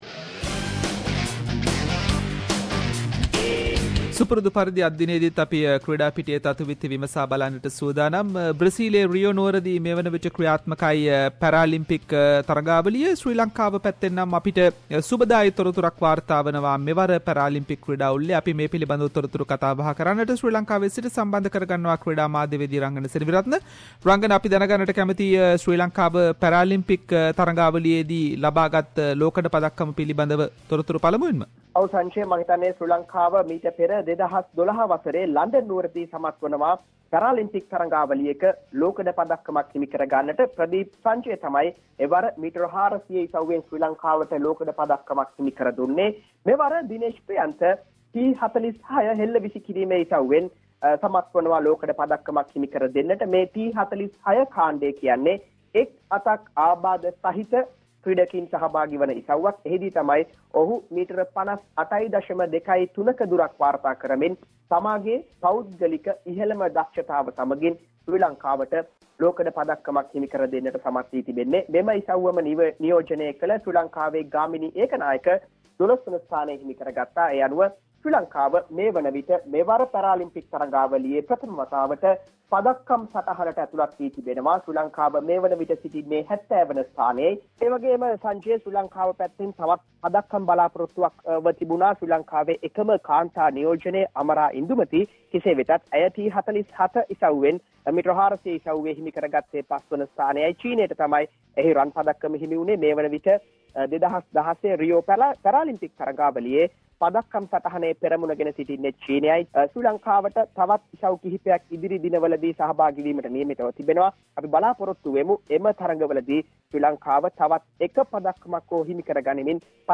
In this week’s SBS Sinhalese sports wrap…. Sri Lanka's Dinesh Priyantha Herath Wins Bronze at Rio Paralympics, Australia Women’s cricket tour to Sri Lanka, Former Sri Lankan test cricketer Pubudu Dassanayake appointed as a USA national cricket coach and many more sport news. Sports journalist